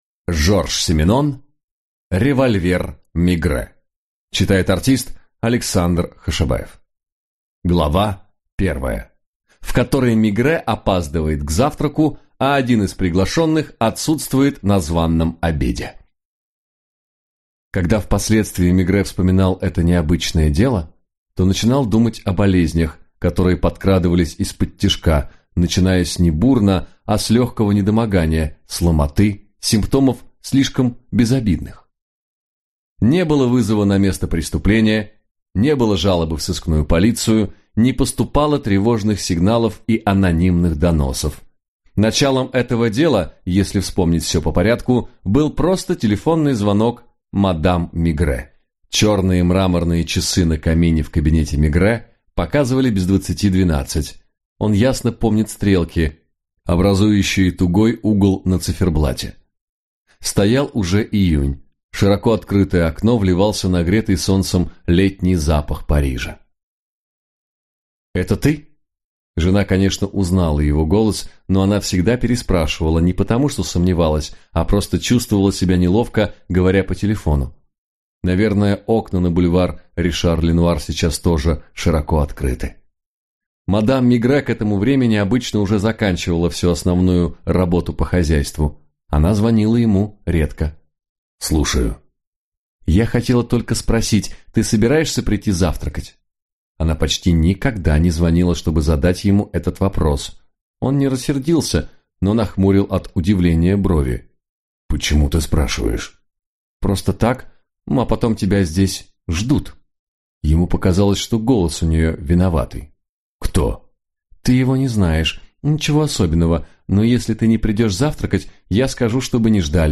Аудиокнига Револьвер Мегрэ | Библиотека аудиокниг